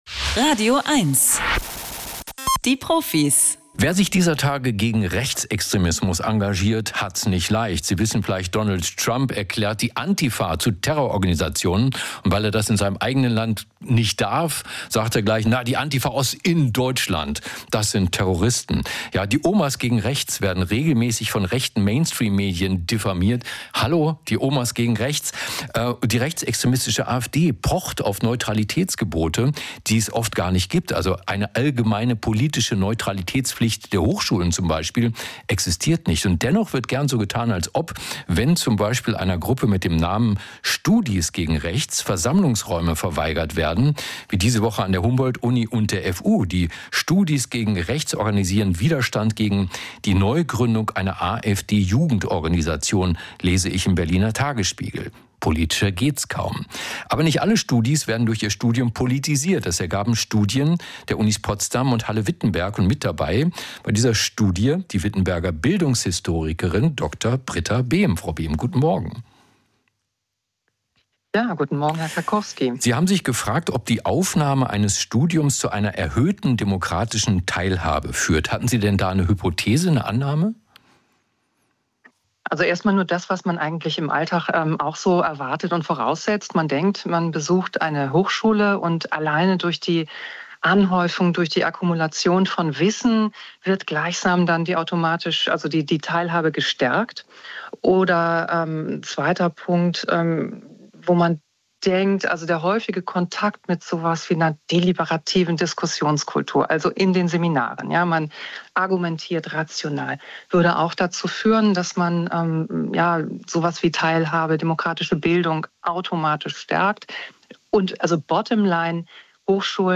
(Interview):